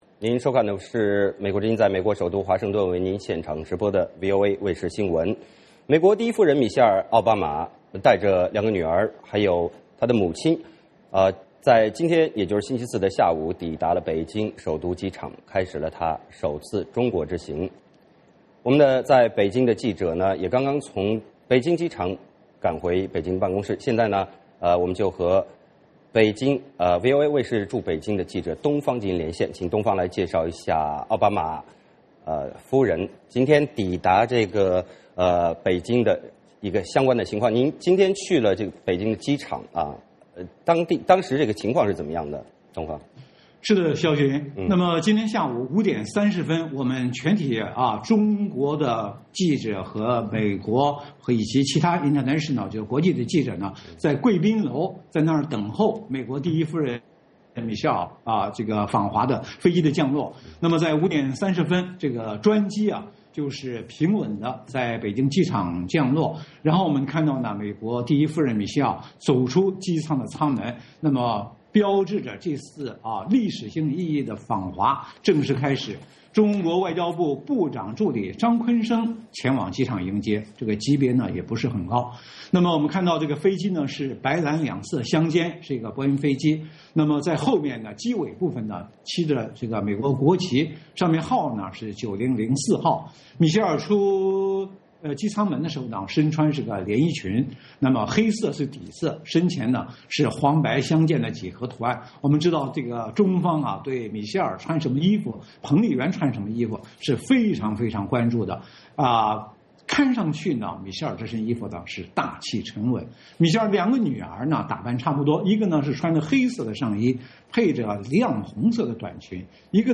VOA连线：美第一夫人抵达北京